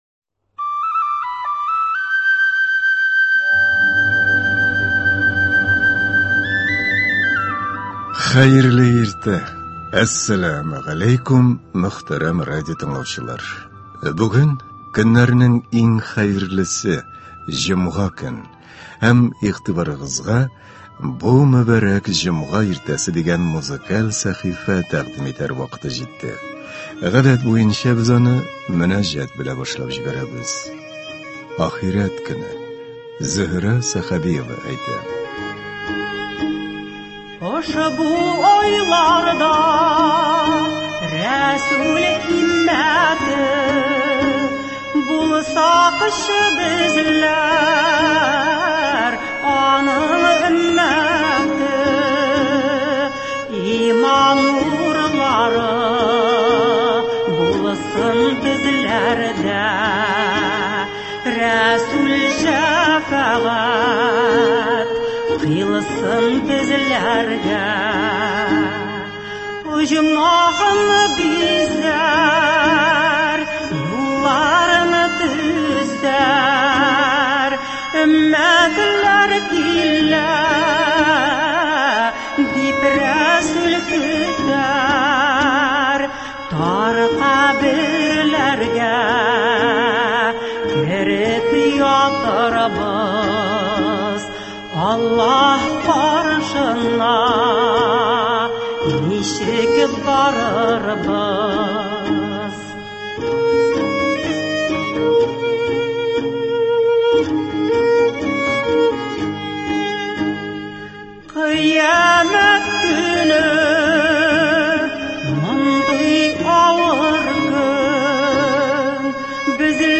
Адәм баласы рухи, мәңгелек темаларга багышланган музыкаль әсәрләргә дә ихтыяҗ кичерә. Бу иртәдә сезнең игътибарыгызга нәкъ шундый концерт тәкъдим итәбез.